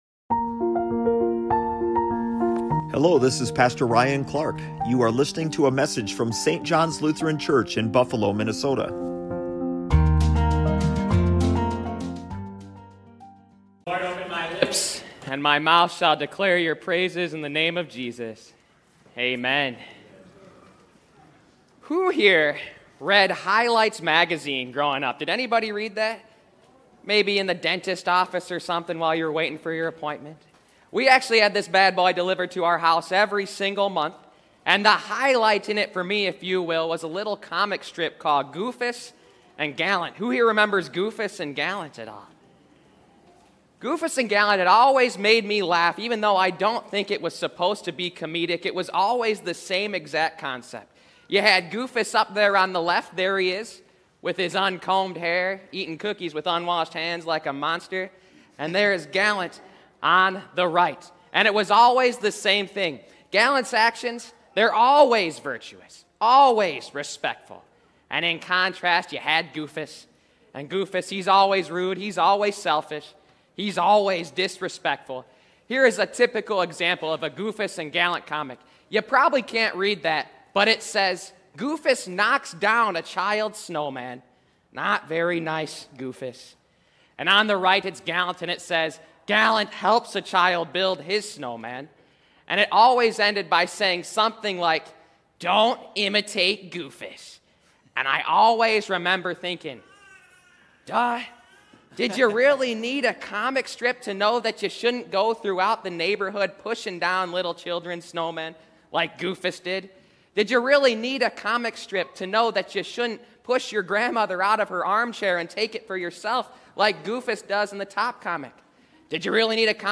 sermon home As Christians, so often we know we're supposed to be like Jesus, but we act in the exact opposite way. What are some things we should keep in mind when we find ourselves doing the opposite of Jesus and how does Jesus transform us to be like Him?